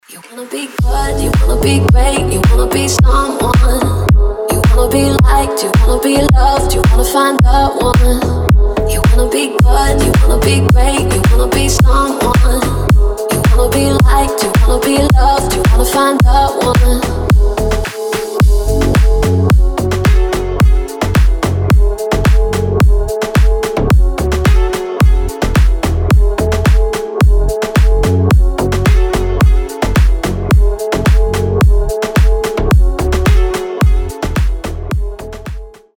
• Качество: 320, Stereo
deep house
атмосферные
женский голос